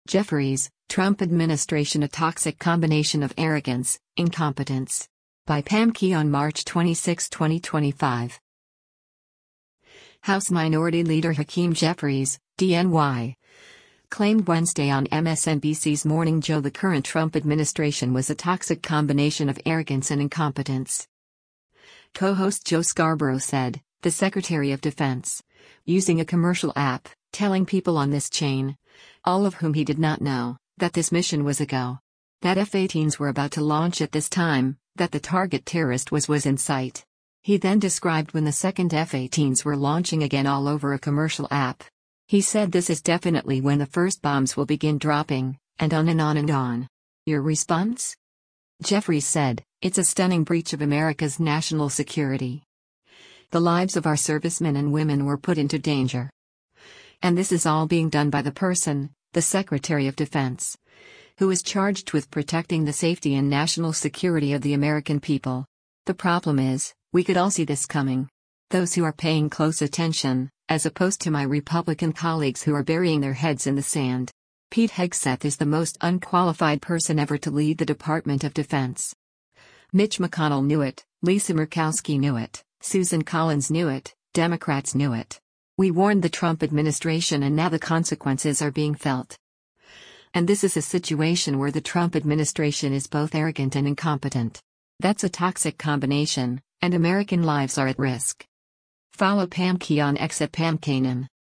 House Minority Leader Hakeem Jeffries (D-NY) claimed Wednesday on MSNBC’s “Morning Joe” the current Trump administration was a “toxic combination” of arrogance and incompetence.